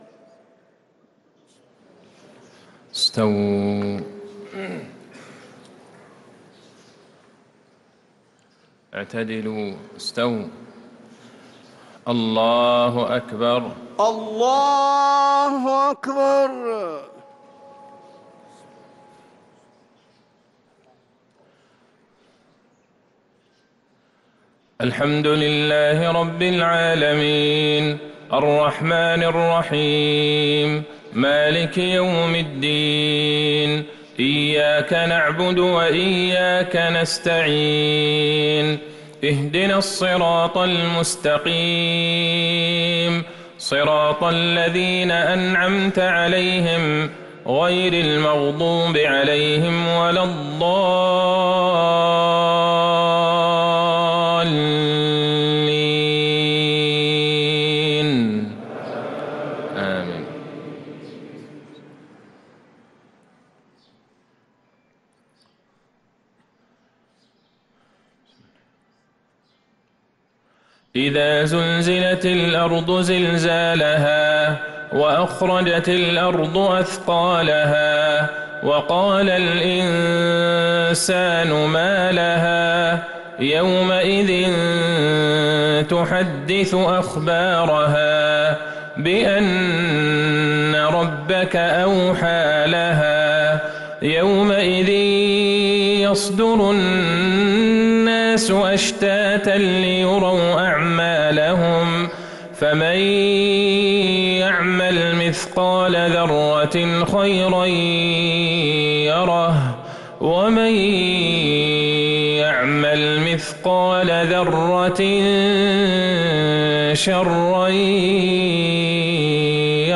صلاة المغرب للقارئ عبدالله البعيجان 19 جمادي الآخر 1445 هـ
تِلَاوَات الْحَرَمَيْن .